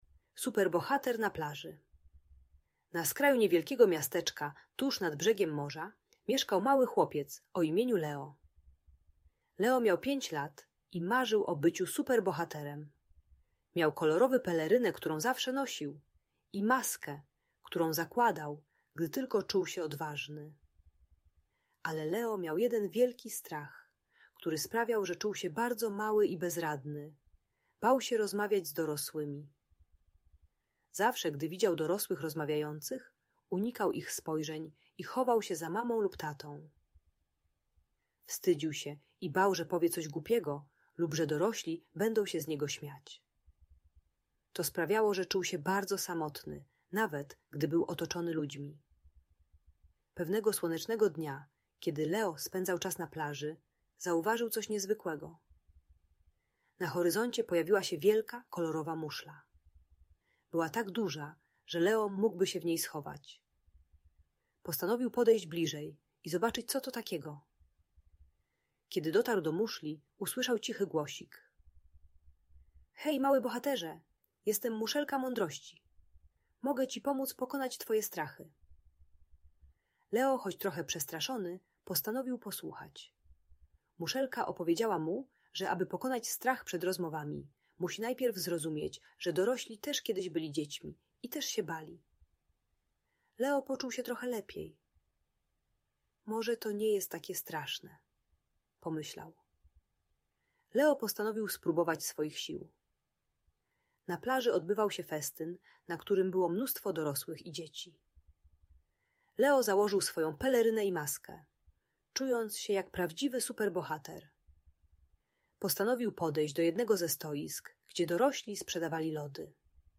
Superbohater na Plaży - historia o odwadze - Audiobajka dla dzieci